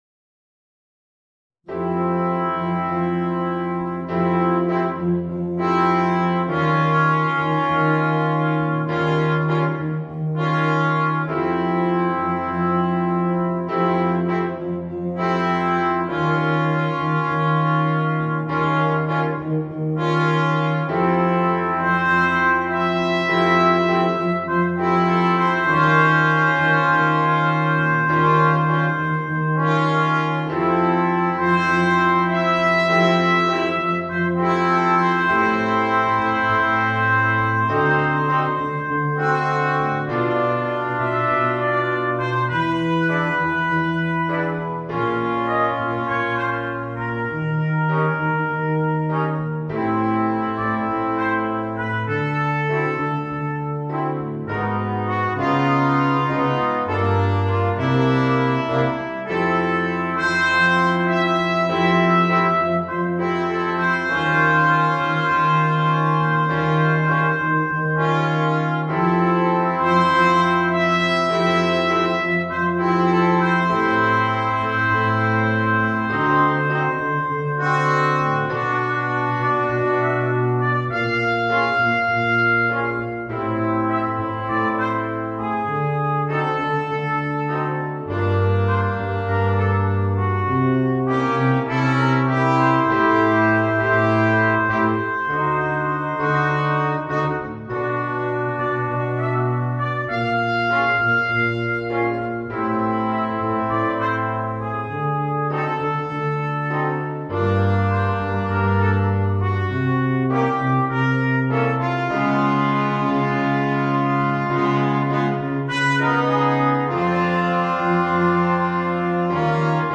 Voicing: 2 Cornets, 2 Euphoniums